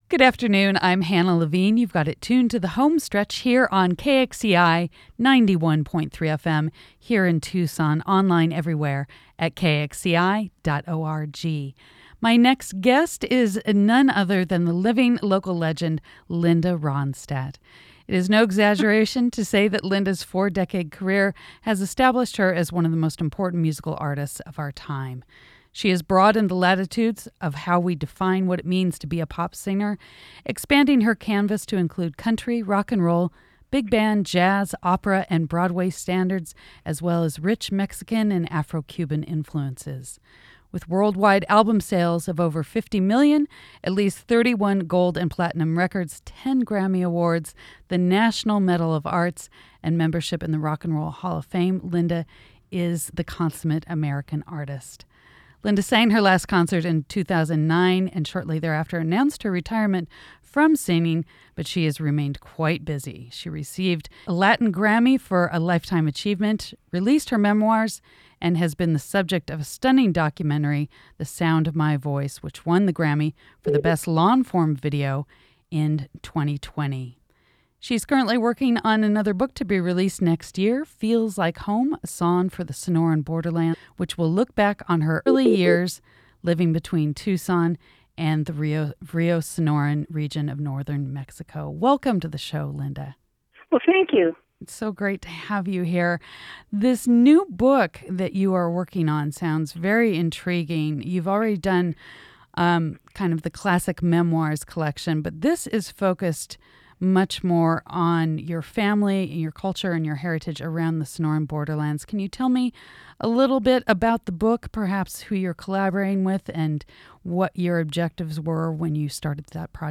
Linda-Ronstadt-and-Mayor-Romero-interview-2021-edited.mp3